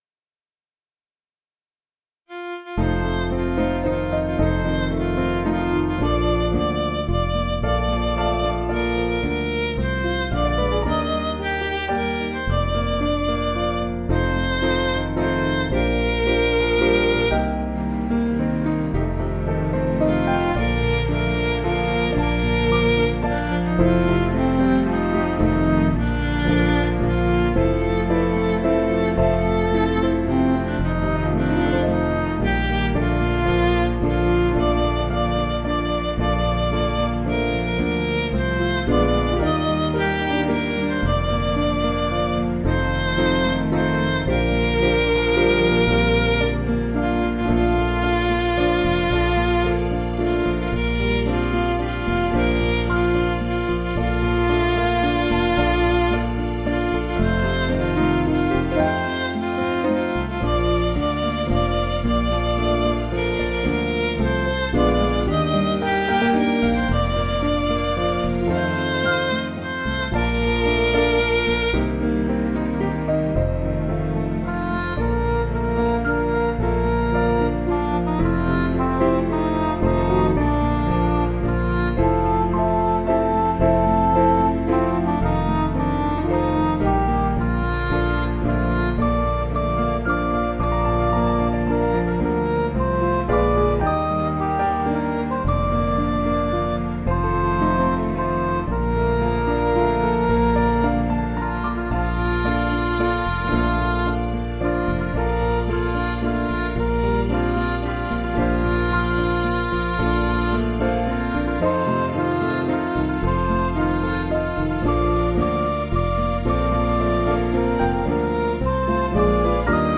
Piano & Instrumental
(CM)   3/Bb
Midi